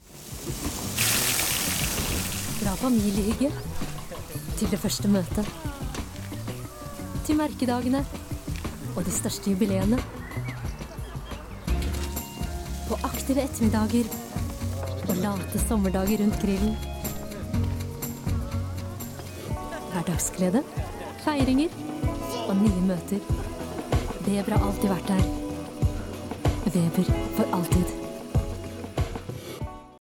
Female
Friendly, Confident, Character, Corporate, Energetic, Natural, Warm, Engaging
Microphone: SM Pro Audio MC01